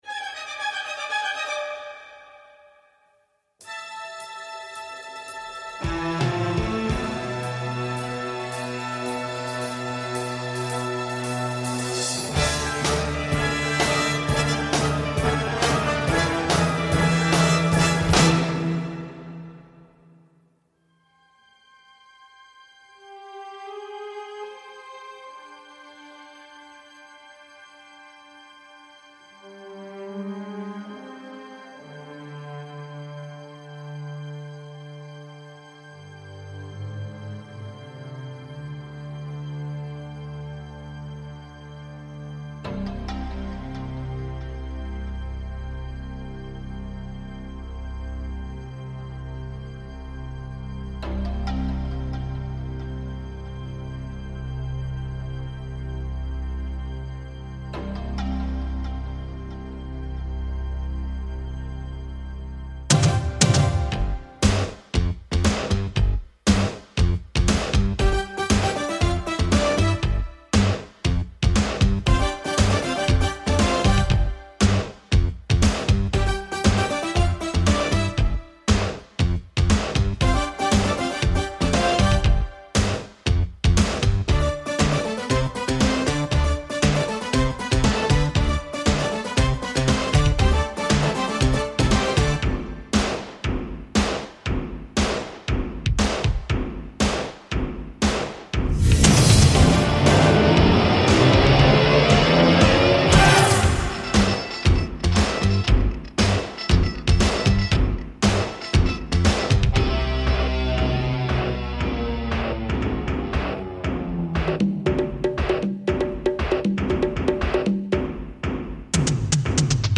à coup de griffures de guitares et basses ronflantes.
Ça balance, c’est fun, et un peu ringue aussi.